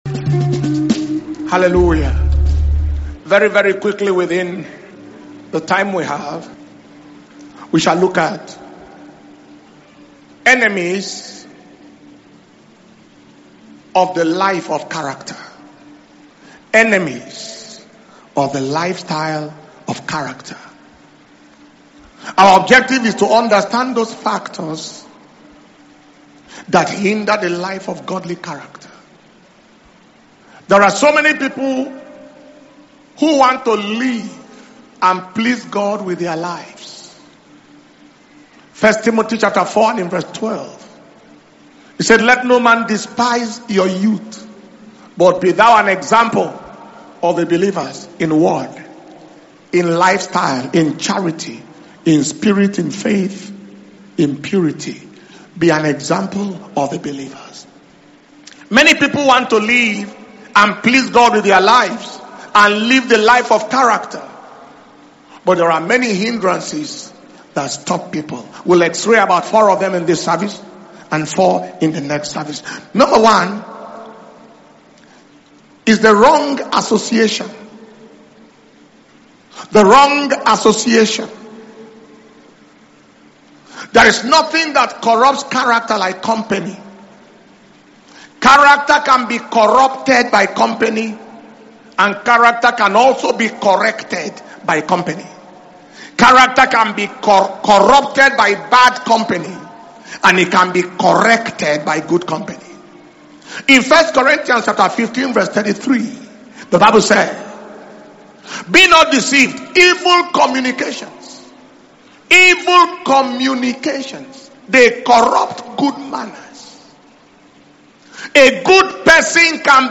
October 2025 Testimony And Thanksgiving Service - Sunday October 26th 2025